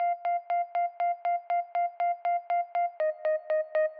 Watching (Bleeps) 120BPM.wav